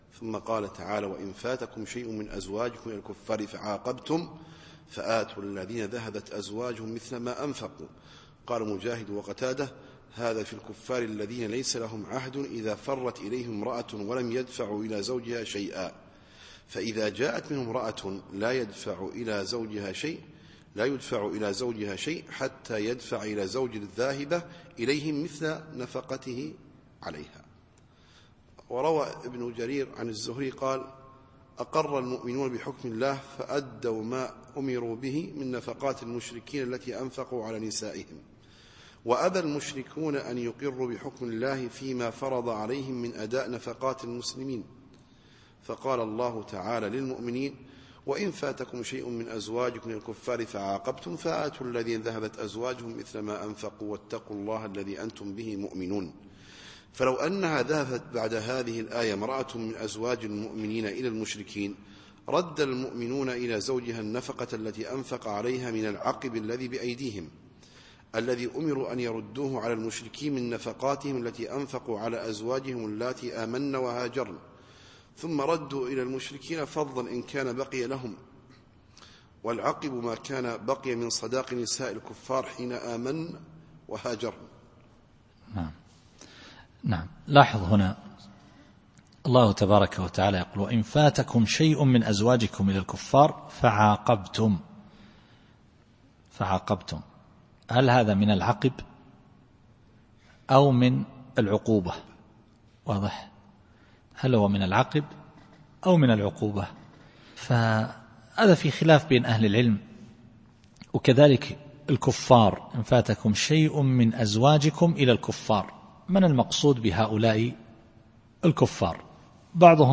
التفسير الصوتي [الممتحنة / 11]